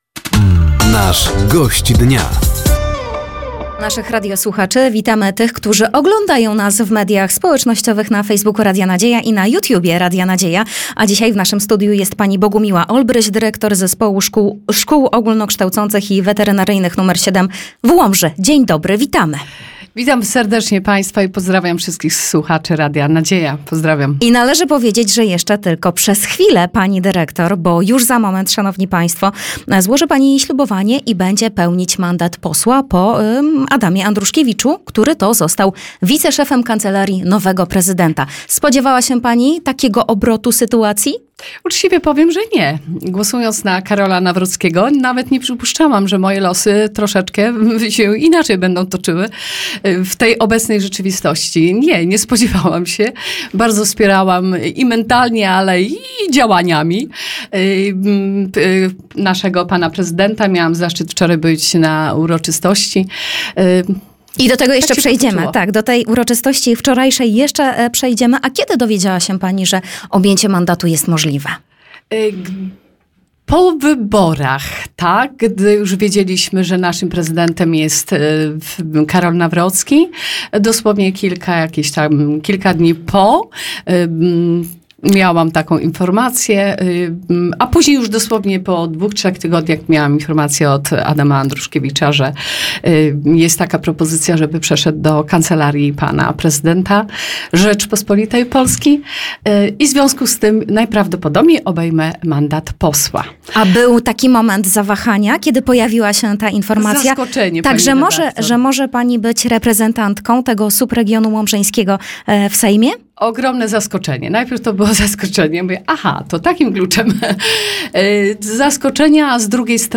Zapraszamy do wysłuchania rozmowy z przyszłą posłanką: